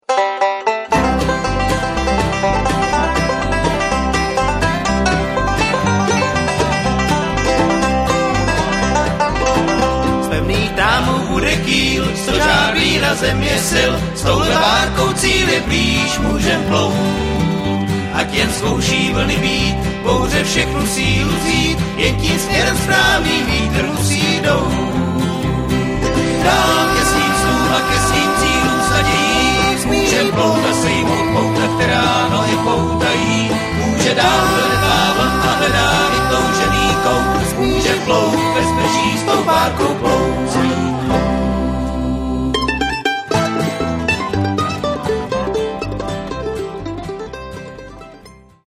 guitar, vocal
mandolin, vocal